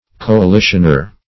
Coalitioner \Co`a*li"tion*er\, n.
coalitioner.mp3